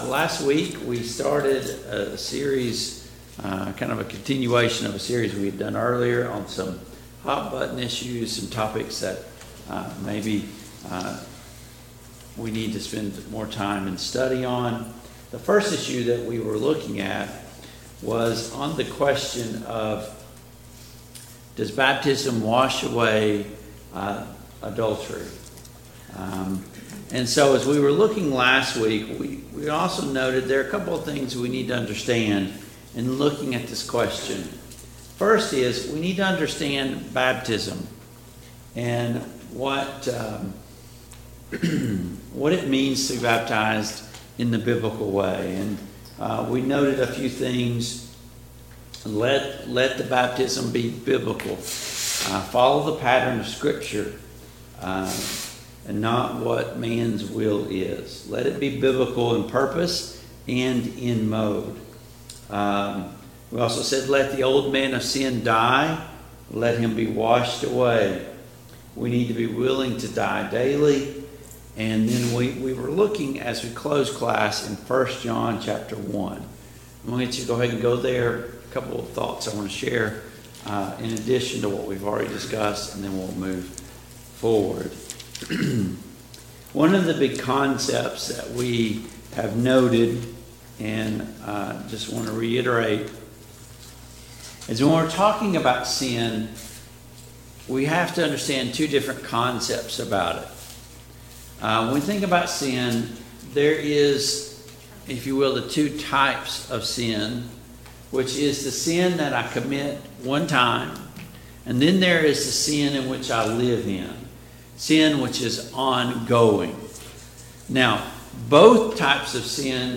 1 John 1:5-9 Service Type: Sunday Morning Bible Class Download Files Notes Topics: Adultery , baptism , Forgiveness , Forgiveness of Sin , Sin « 22.